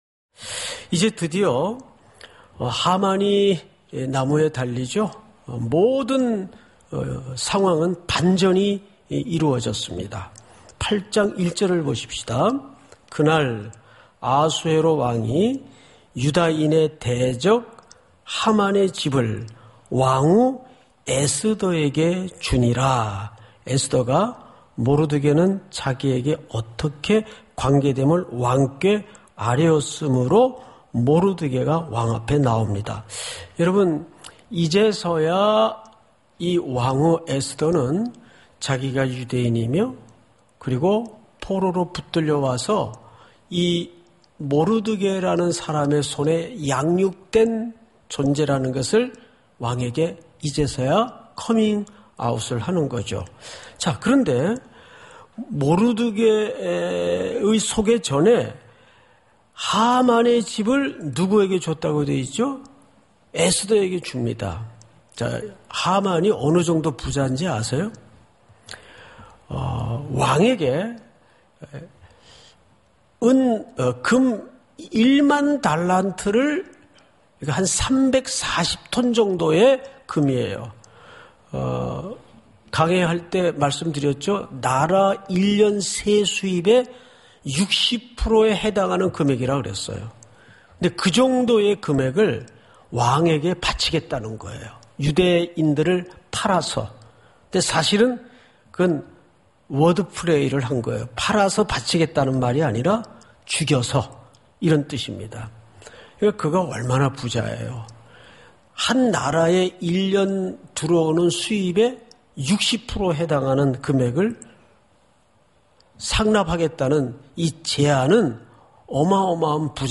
주일저녁예배